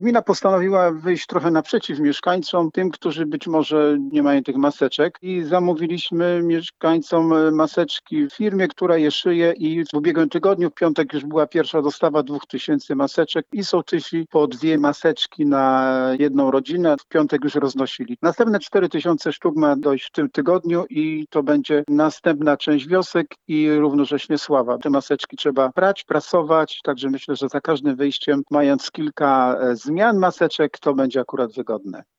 – Chcemy w ten sposób pomóc naszym mieszkańcom w walce z epidemią – powiedział Krzysztof Gruszewski, zastępca burmistrza: